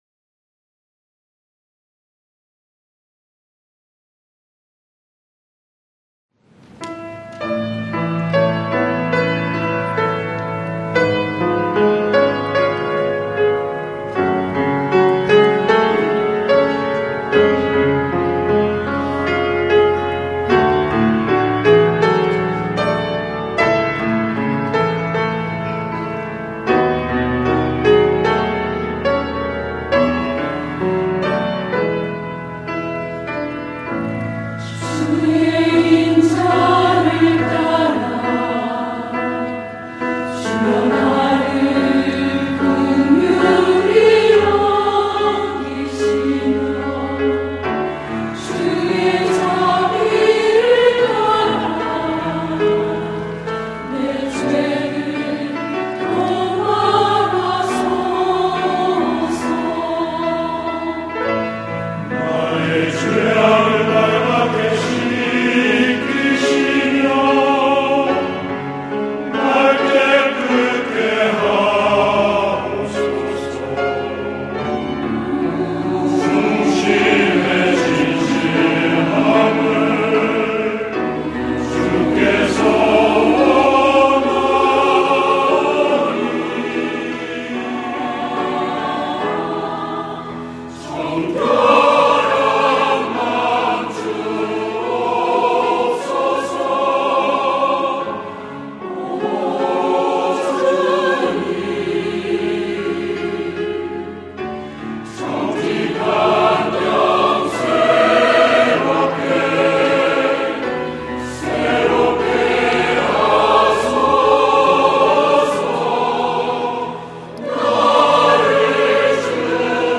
시편 51편 > 찬양영상